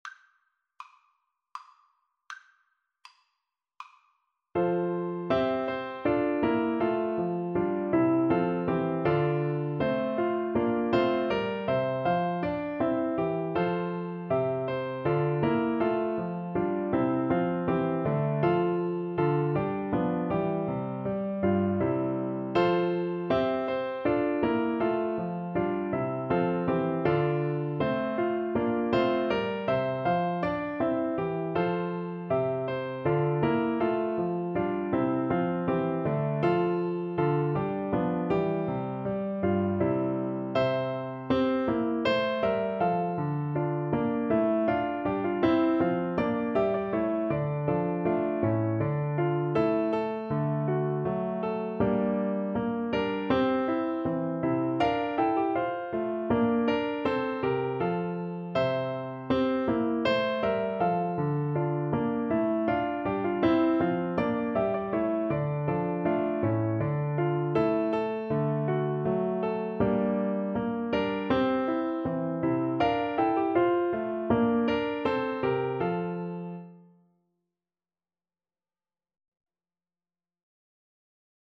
=c.80
3/2 (View more 3/2 Music)
F major (Sounding Pitch) (View more F major Music for Oboe )
Classical (View more Classical Oboe Music)